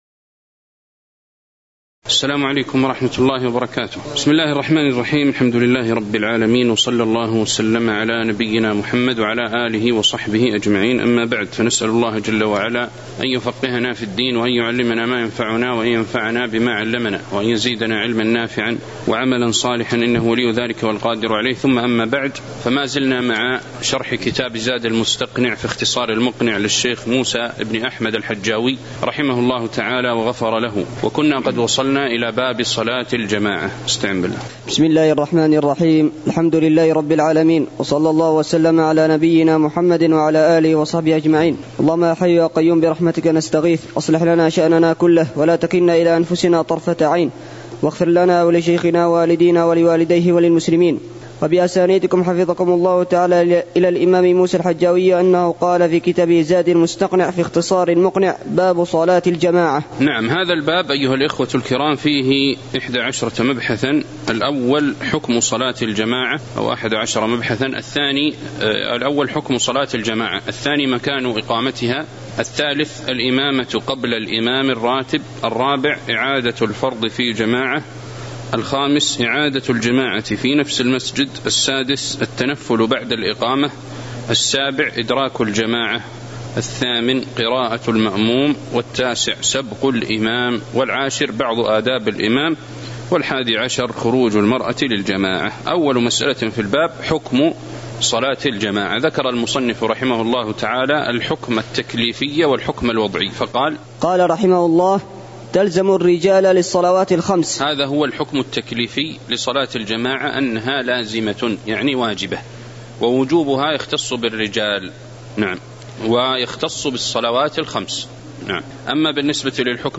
تاريخ النشر ٦ صفر ١٤٤٠ هـ المكان: المسجد النبوي الشيخ